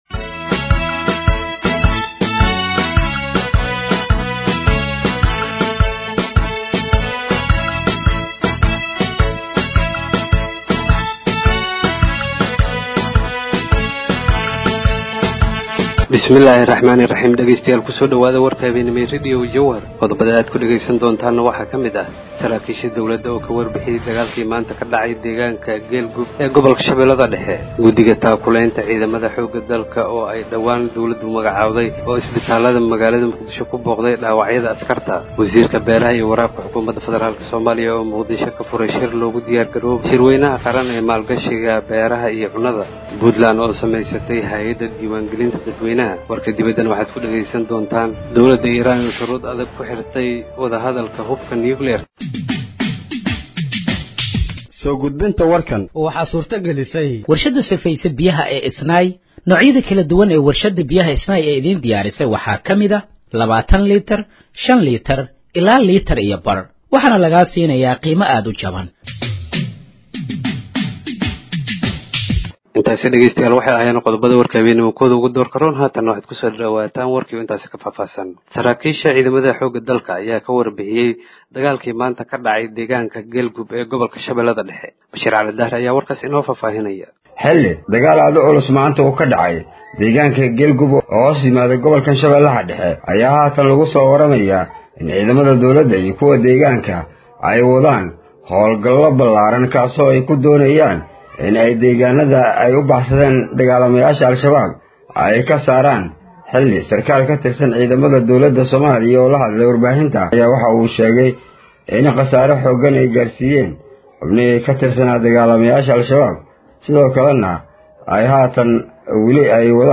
Dhageeyso Warka Habeenimo ee Radiojowhar 09/04/2025
Halkaan Hoose ka Dhageeyso Warka Habeenimo ee Radiojowhar